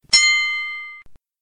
Trylky - znělky
SPUSTIT/STÁHNOUT | Trylek "Brlm"